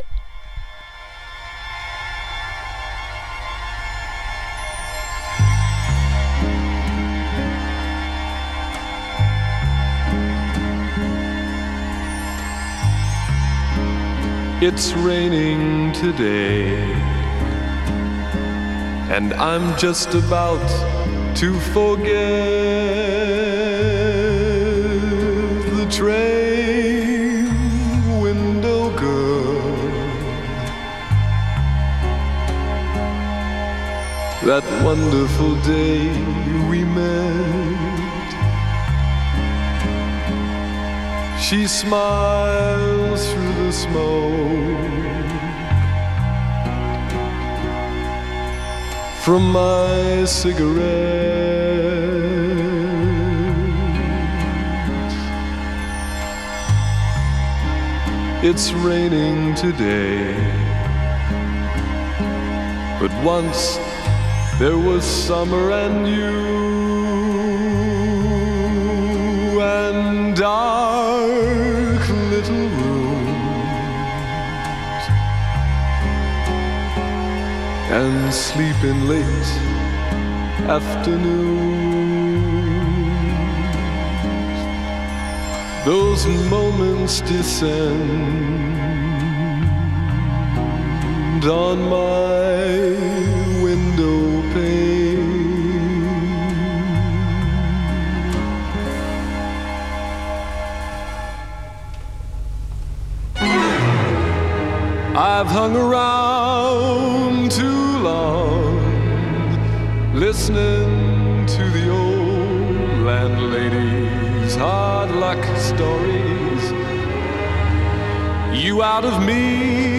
(audio capture from web stream)